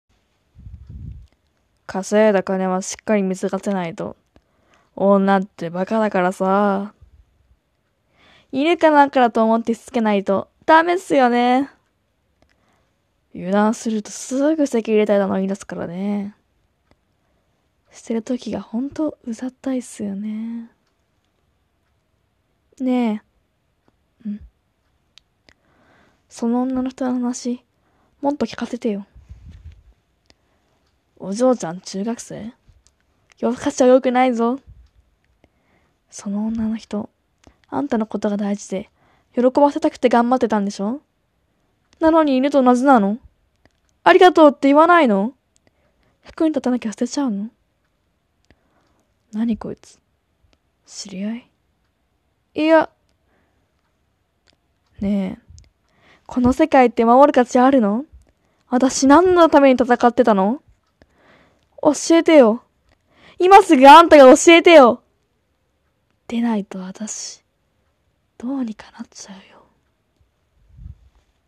まどマギ 声劇